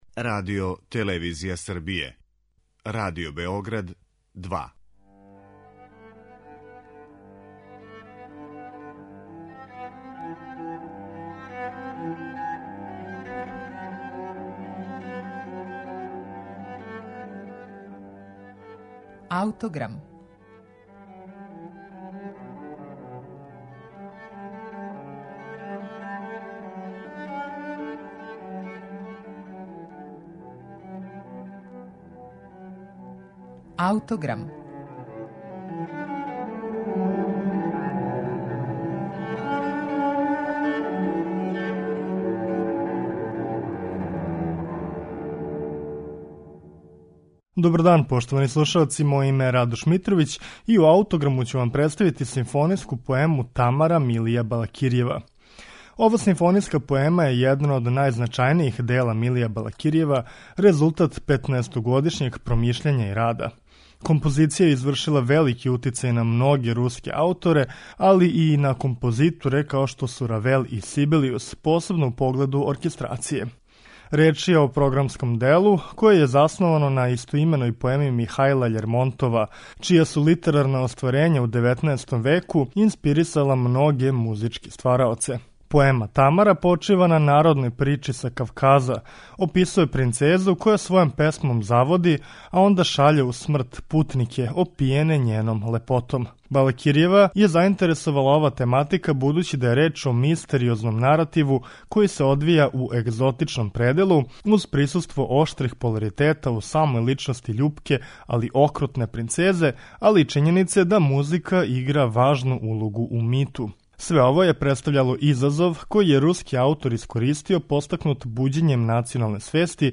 Симфонијска поема 'Тамара', Милија Балакирјева
Реч је о програмском делу које је засновано на истоименој поеми Михаила Љермонтова, чија су литерарна остварења у 19. веку инспирисала многе музичке ствараоце. Симфонијску поему Тамара, Милија Балакирјева, слушаћемо у извођењу Државног академског симфонијског оркестра под управом Јевгенија Светланова.